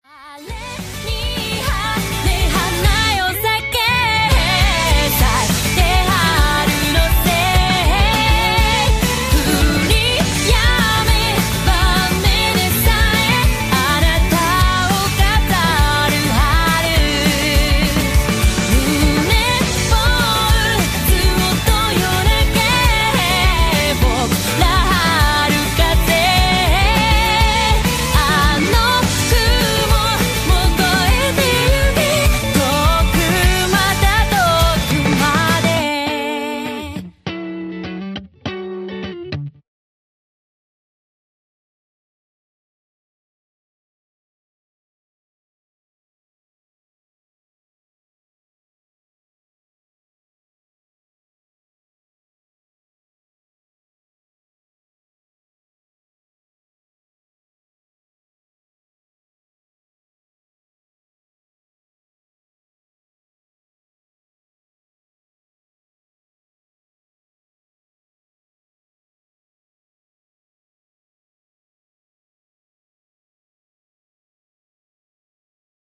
applause.ogg